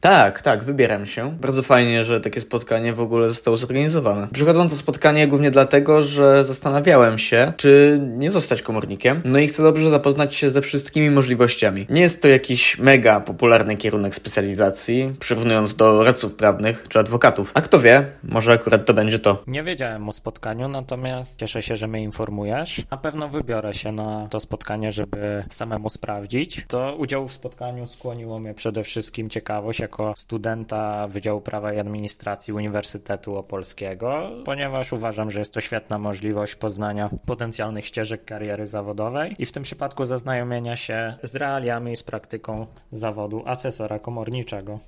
Mieliśmy przyjemność zapytać studentów naszego Uniwersytetu